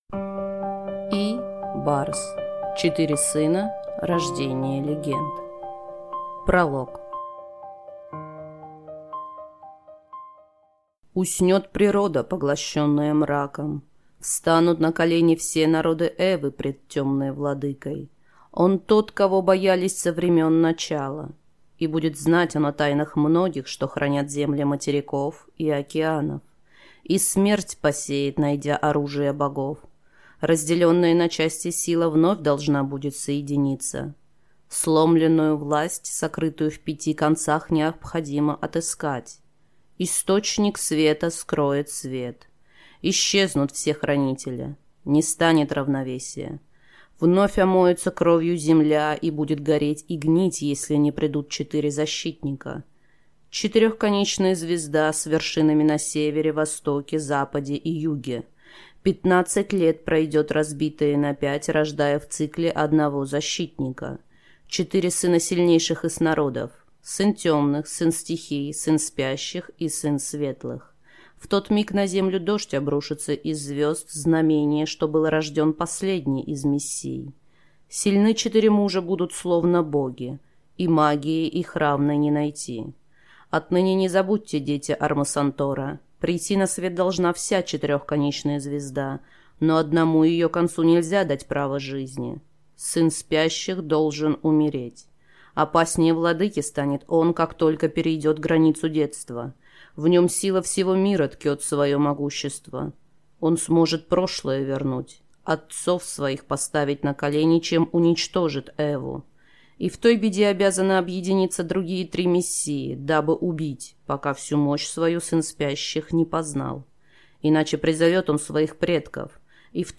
Аудиокнига Четыре сына. Рождение легенд | Библиотека аудиокниг